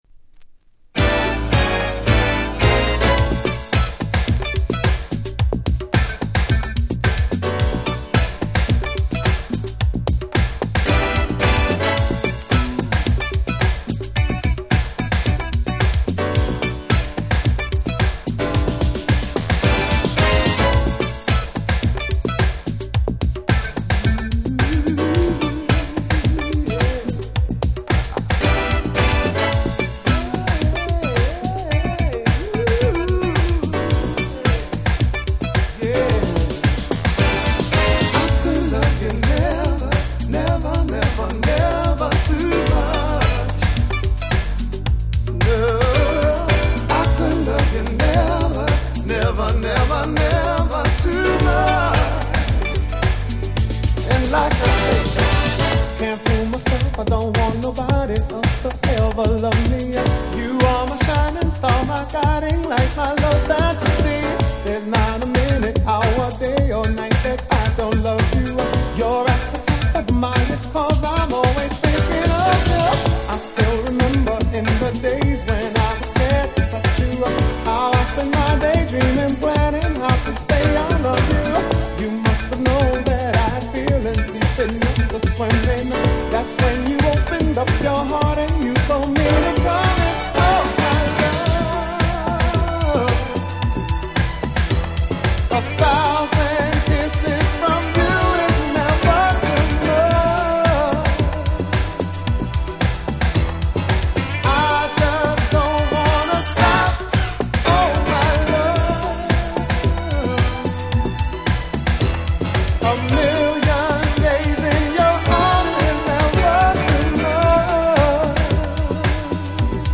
81年の鉄板Dance Classicの後発Remix。
よりフロア向けに味付けされたRemixは元より、素晴らしすぎるOriginalをちゃんと収録しているのも最強。